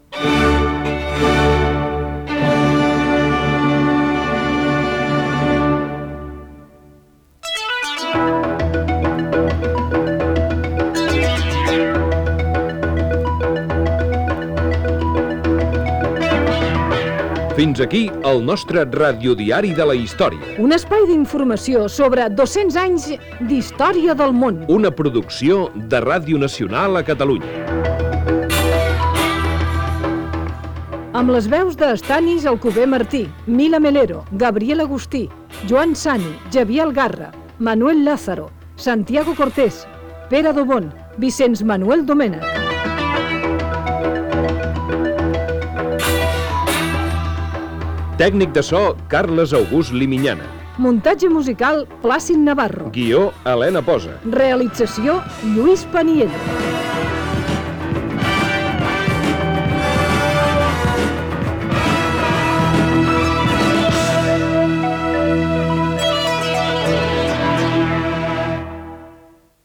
Música i careta de sortida amb els noms de l'equip